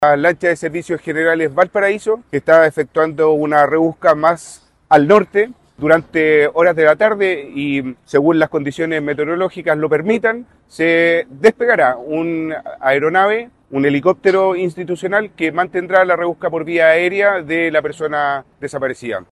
El Capitán de Puerto de Valparaíso, Capitán de Fragata David López, se refirió al método de búsqueda.